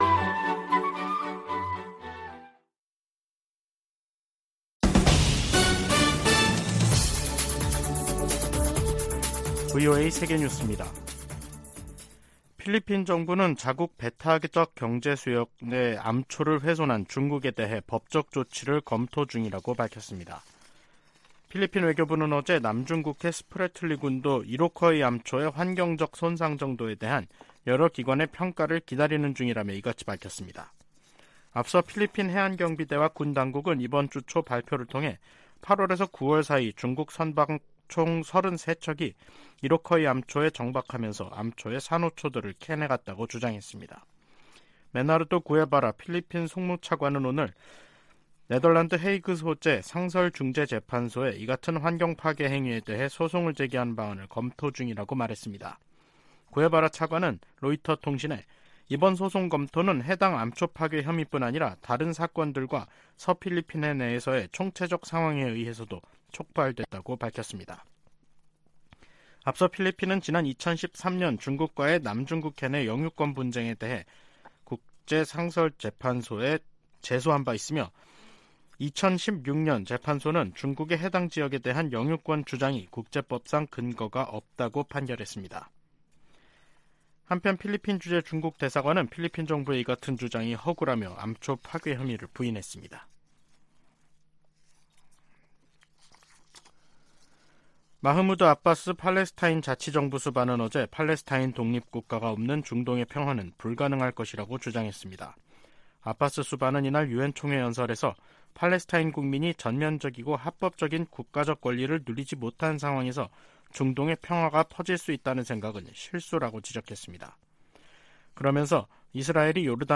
VOA 한국어 간판 뉴스 프로그램 '뉴스 투데이', 2023년 9월 22일 2부 방송입니다. 북한이 우크라이나 전쟁에 쓰일 무기 등 관련 물자를 러시아에 지원할 경우 제재를 부과하도록 하는 법안이 미 하원에서 발의됐습니다. 한국 정부가 북-러 군사협력에 대해 강경 대응을 경고한 가운데 러시아 외무차관이 조만간 한국을 방문할 것으로 알려졌습니다. 발트 3국 중 하나인 리투아니아가 북-러 무기 거래 가능성에 중대한 우려를 나타냈습니다.